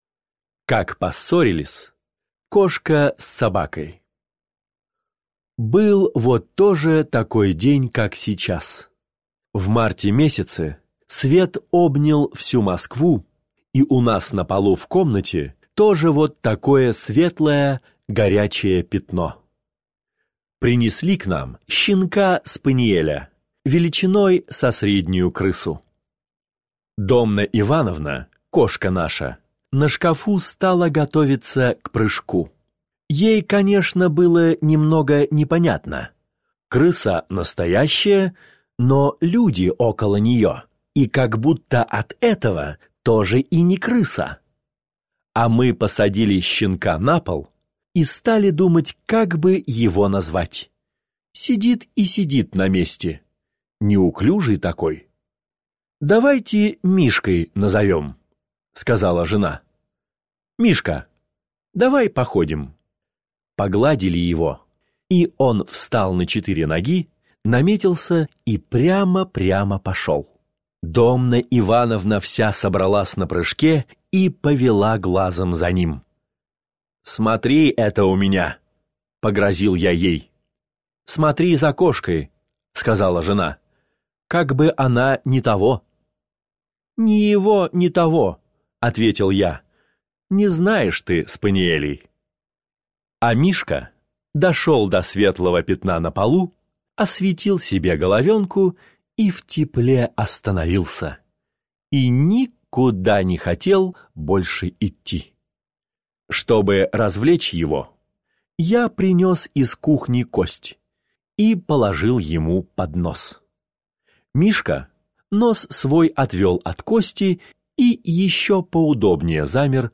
Как поссорились кошка с собакой – Пришвин М.М. (аудиоверсия)